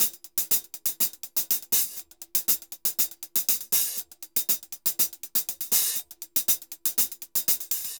HH_Salsa 120_1.wav